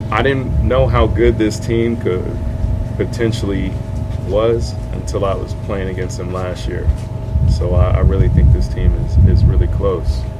Pham says he chose the Pirates over the Padres because he likes the opportunity to play regularly, and he believes they can win.